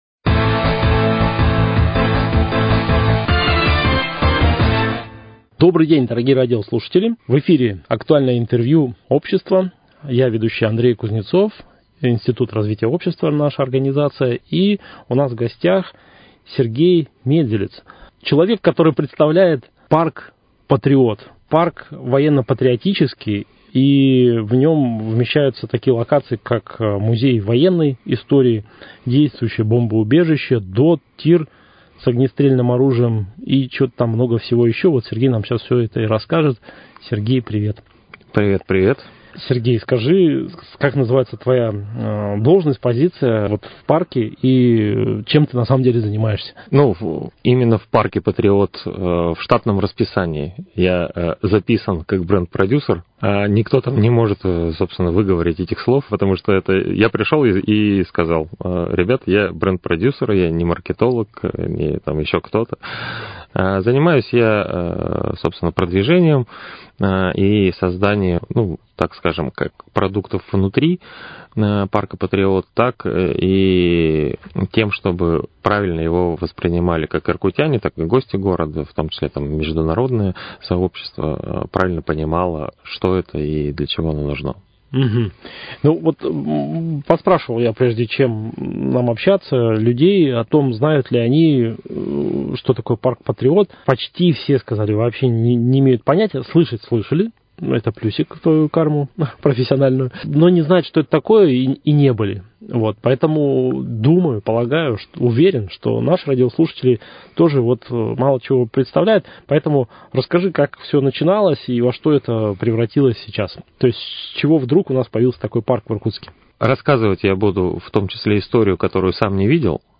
Актуальное интервью.